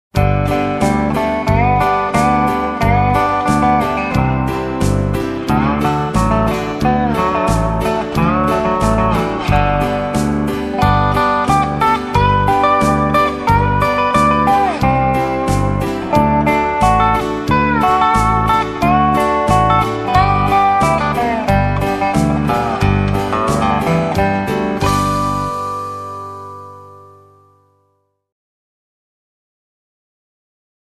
Všechna cvičení jsou nahrána na přiloženém CD.
Ukázka CD dobro(mp3)